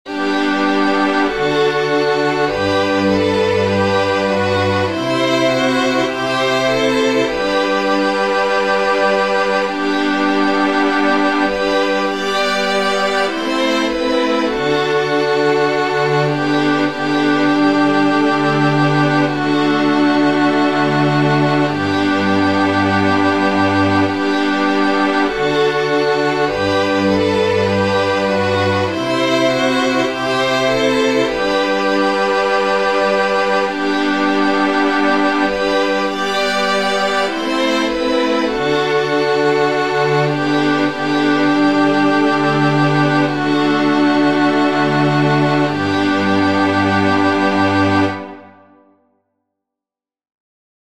• Catégorie : Chants de Prière universelle